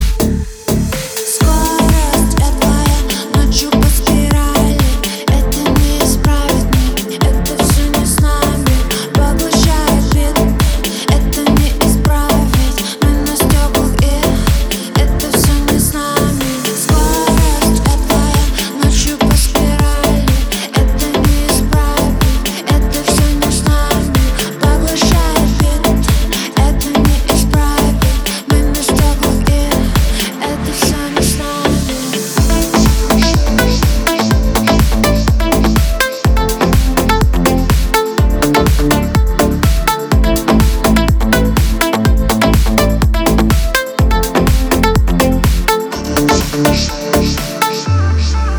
• Качество: 320, Stereo
поп
deep house
чувственные